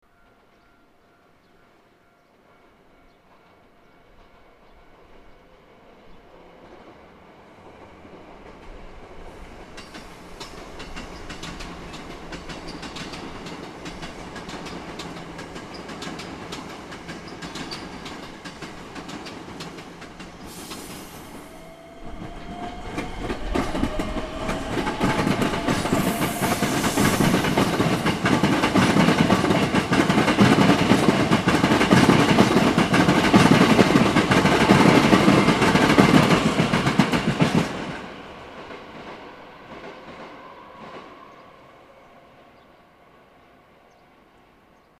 阪急京都線と千里線がクロスする淡路駅の風景。
まっすぐ淡路駅を出て行く京都線の梅田行
まっすぐ淡路駅を出て行く京都線の梅田行き普通電車。
このパターンの音　（先に京都方面行きが通ります。）